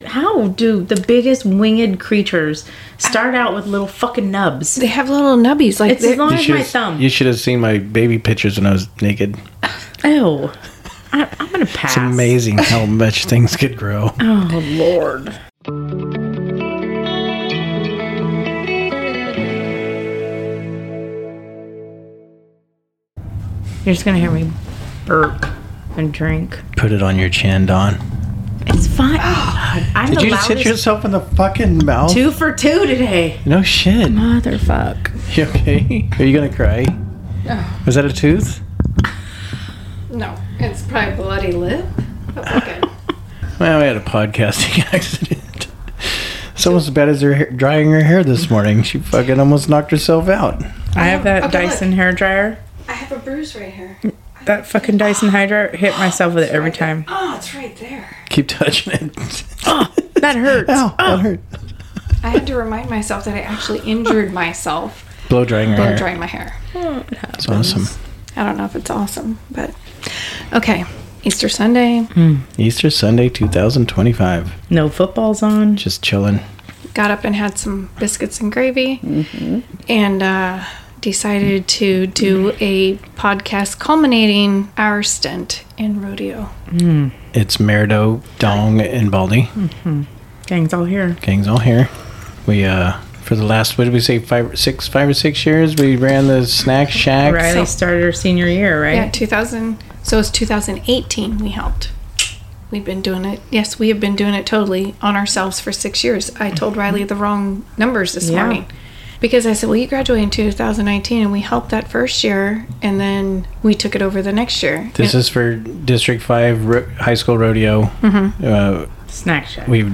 Connecting with special guests every week, our hosts provide a comedic rendition of events that have happened in their lives.